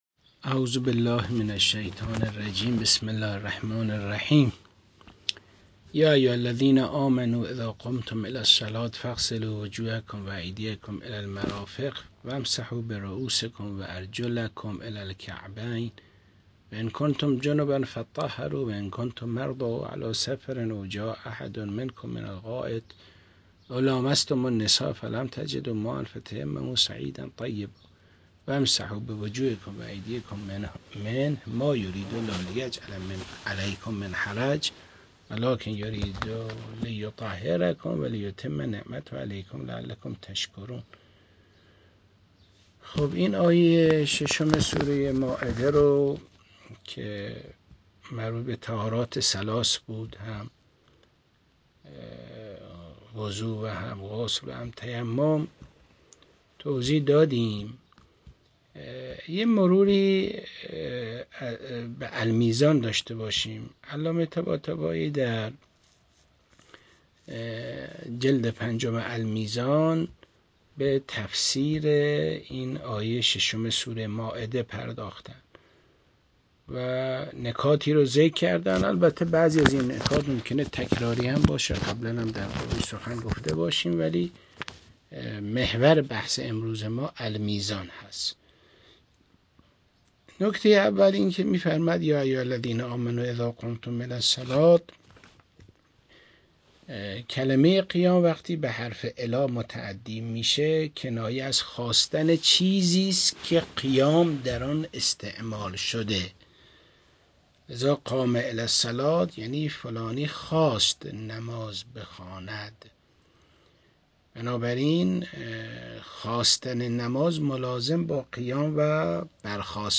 منبر صوتی حجت الاسلام خسروپناه-۴؛